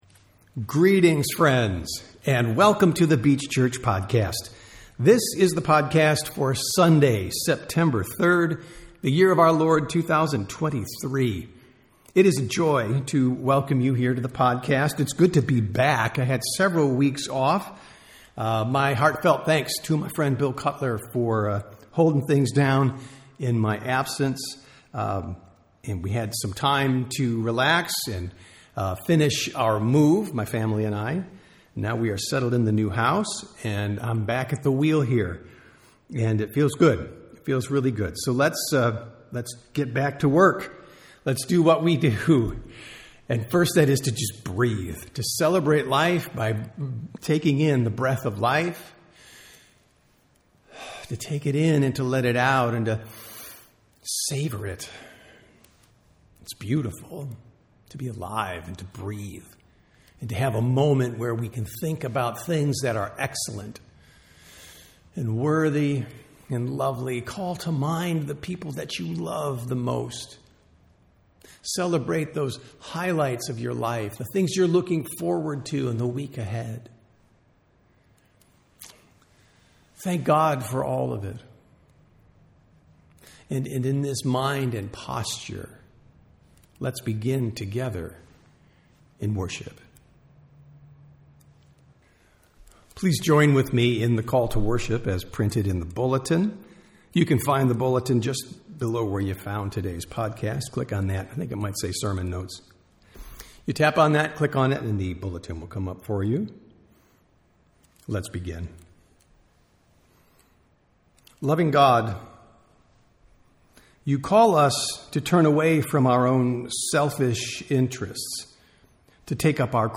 Sunday Worship - September 3, 2023